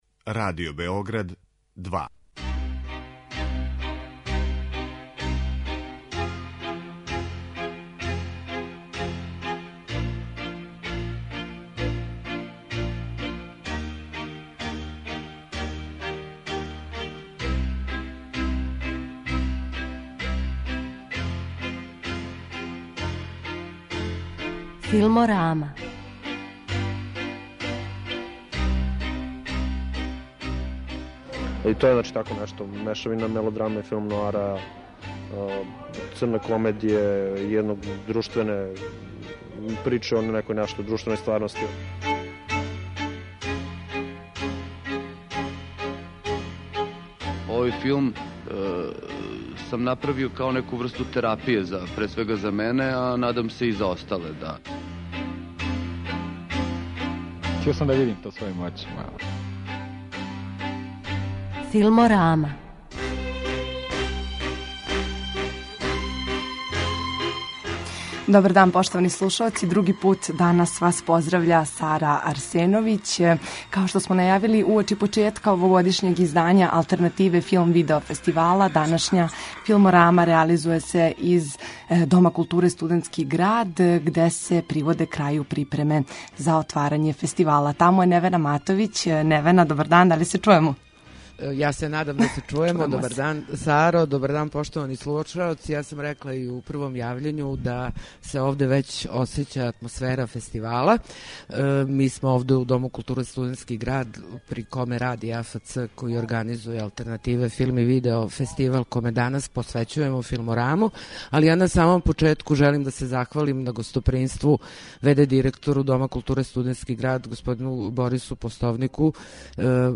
Уочи почетка овогодишњег издања Алтернативе филм/видео фестивала, данашњу Филмораму реализујемо из АФЦ-а Дома културе „Студентски град" , где се приводе крају припреме за отварање.